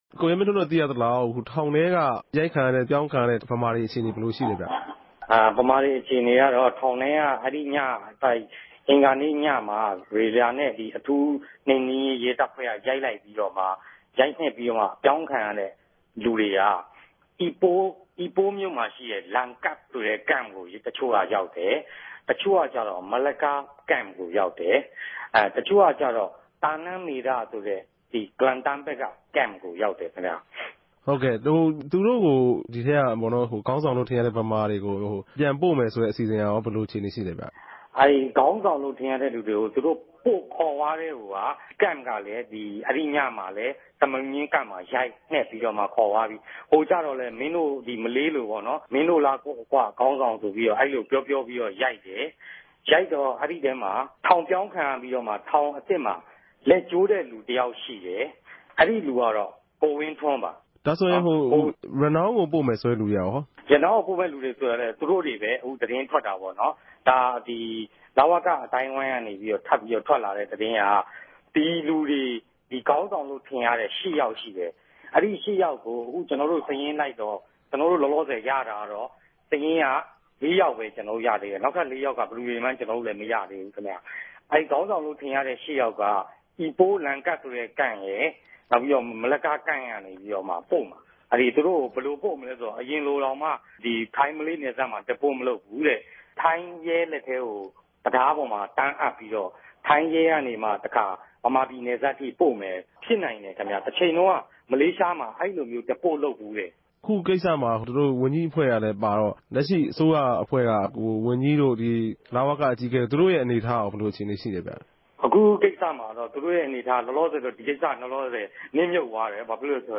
ဆက်သြယ် မေးူမန်းခဵက်။